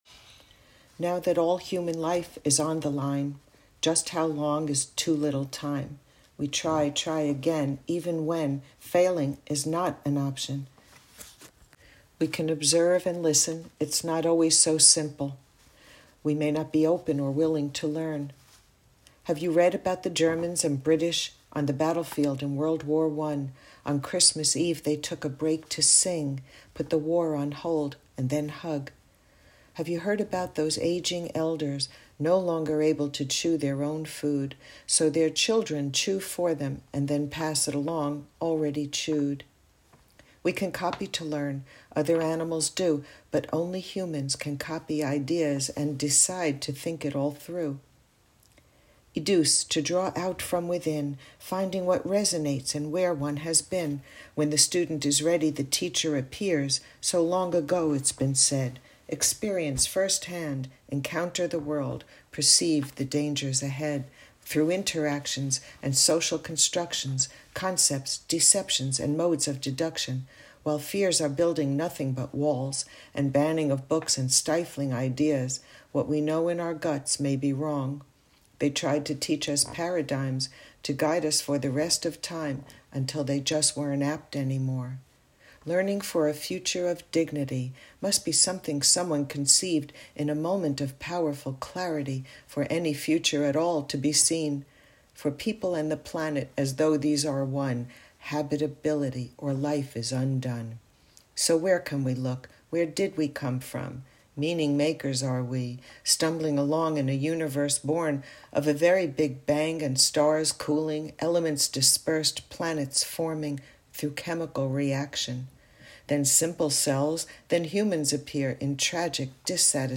spoken poem composed for the specific theme of this year's workshop (Video | spoken recording on November 18, 2022 | Pdf), a contribution to the First World Dignity University Initiative Workshop, titled "For People and the Planet: Learning for a Future of Dignity," hosted online on December 9, 2022, representing the 19th Annual Workshop co-hosted by Morton Deutsch International Center for Cooperation and Conflict Resolution at Teachers College, Columbia University.